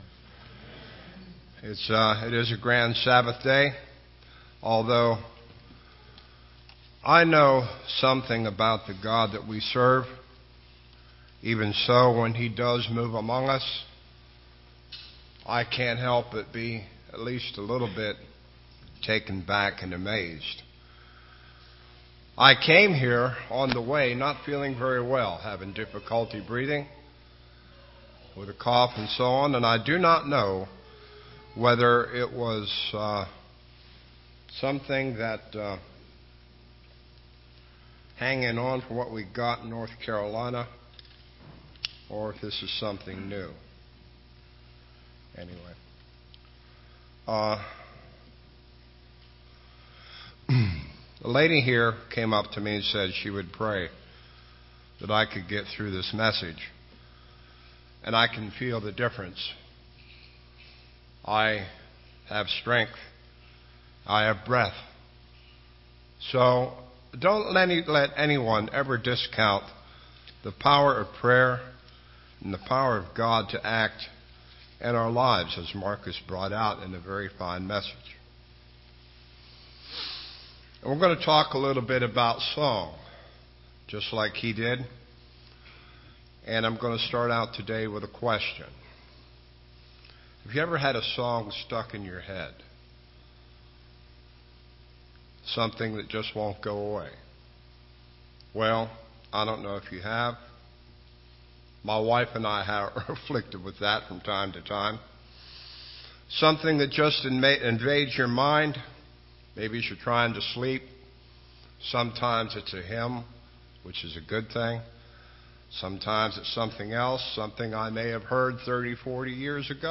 Given in Rome, GA